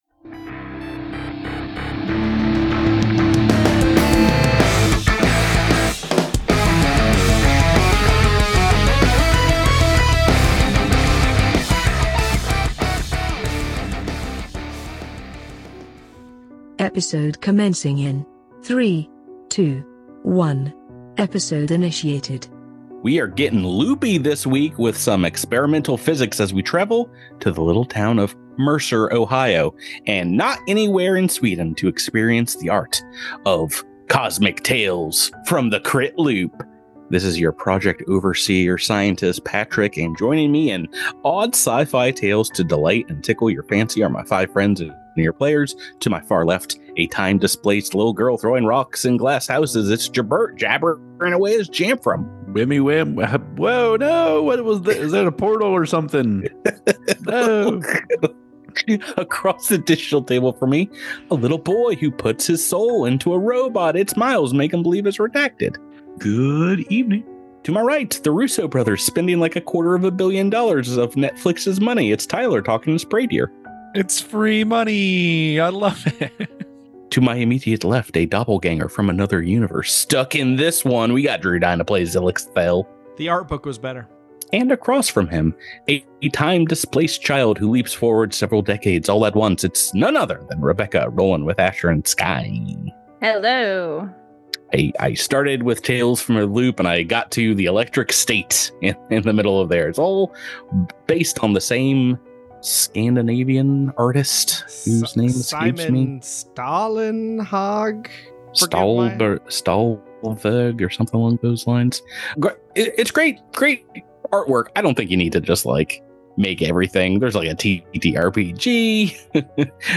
Cosmic Crit is a weekly Actual Play podcast centered on the new Starfinder RPG from Paizo. Listen to the shenanigans as a seasoned GM, a couple of noobs, and some RPG veterans explore the galaxy and fight monsters on behalf of the Starfinder Society. It's a little roleplay, a lot of natural 20s (we hope), and plenty of fun.